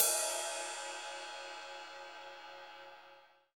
CYM RIDE301R.wav